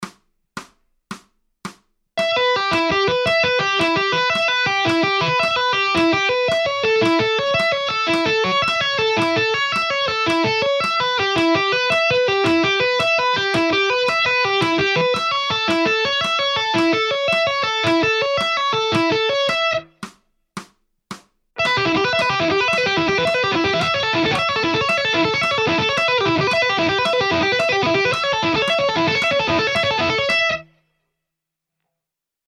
JASON BECKER ARPEGGI IN TAPPING